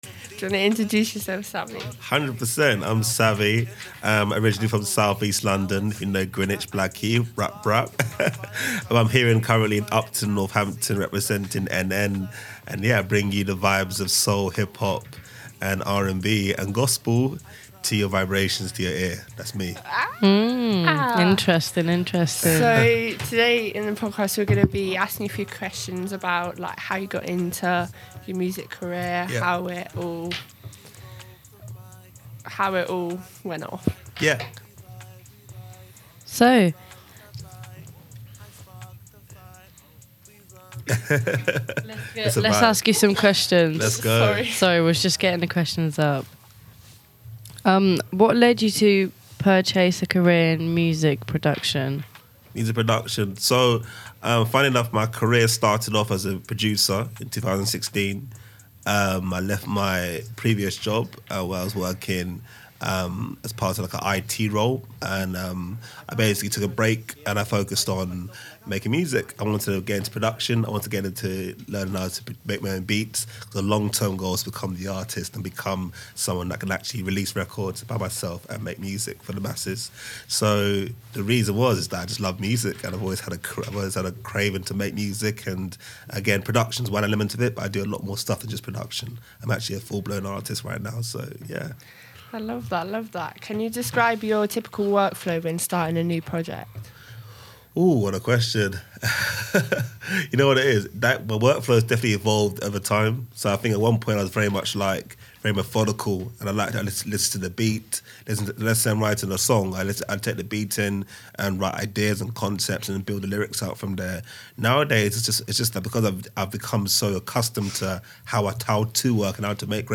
Hosted by our talented young creators, this in-depth conversation dives into the creative world of the multi-instrumentalist and lyricist from South East London.